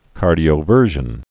(kärdē-ō-vûrzhən)